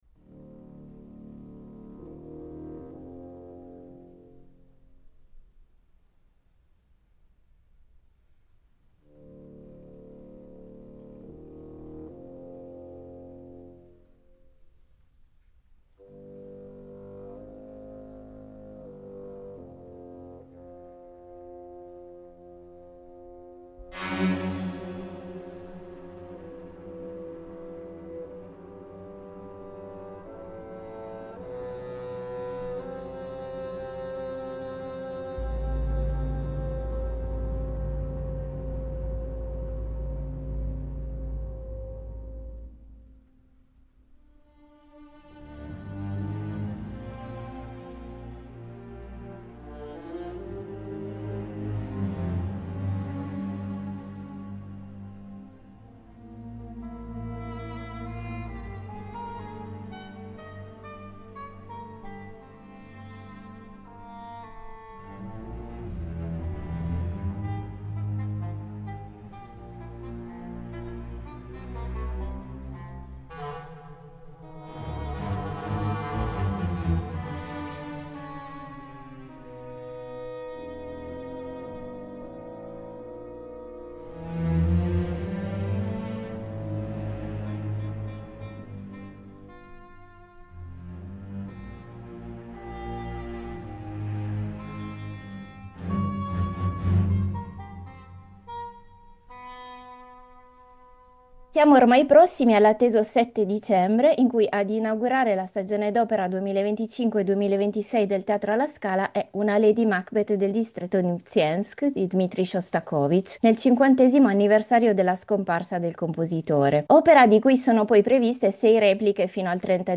Incontro con Carlo Boccadoro